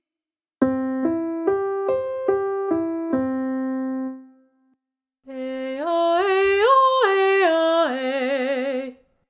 Arpeggios
As an example, the letter “m” is added to each vowel in the audio below:[/su_box]
Instead of going through all five vowels with this extended arpeggio, try choosing two vowels to alternate, such as “a” and “e”:
C4 – E4 – G4 – C5 – G4 – E4 – C4